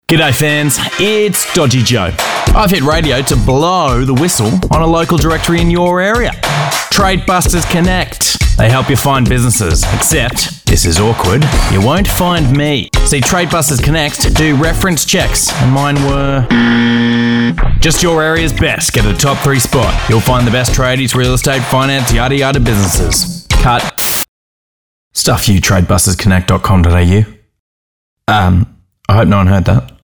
Tradebusters-Connect-30sec-Radio-Ad-Final-MP3.mp3